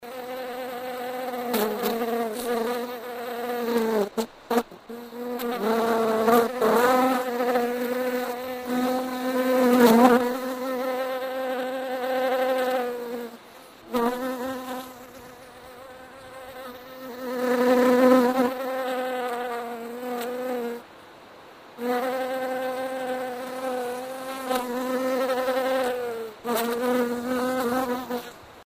На этой странице собраны разнообразные звуки пчел: от одиночного жужжания до гула целого роя.
Звук полета пчелы в поисках пыльцы